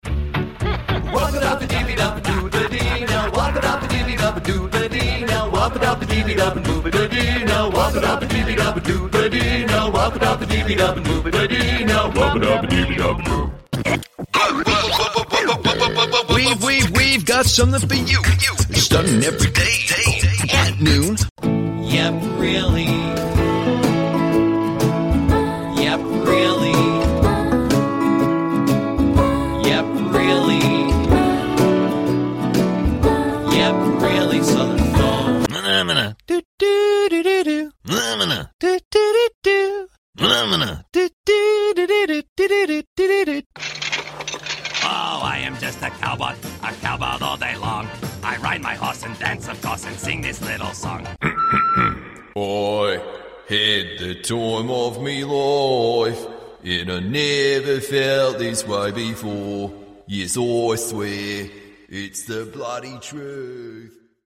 Male
Singing
Variety Of Singing/Jingles